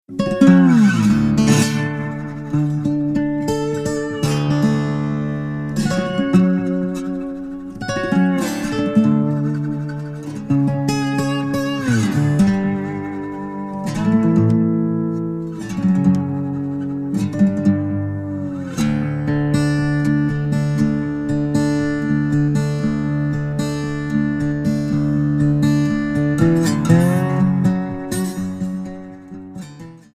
Alternative,Blues